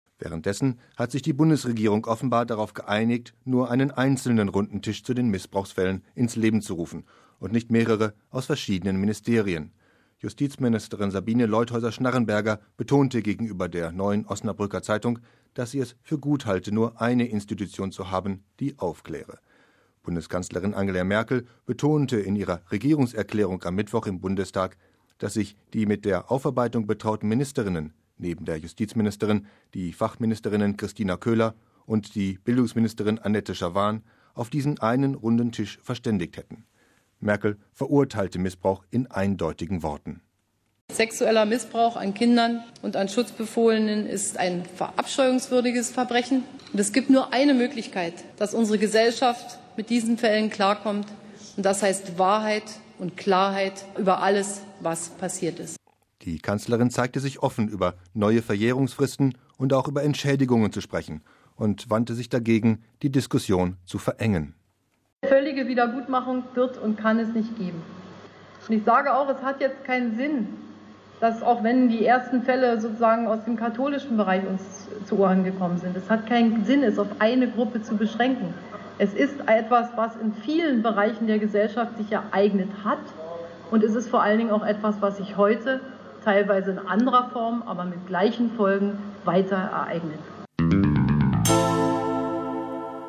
Bundeskanzlerin Angela Merkel erklärte in ihrer Regierungserklärung am Mittwoch im Bundestag, dass sich die mit der Aufarbeitung betrauten Ministerinnen – neben der Justizministerin die Familienministerin Kristina Köhler und die Bildungsministerin Annette Schavan – auf diesen einen Runden Tisch verständigt hätten.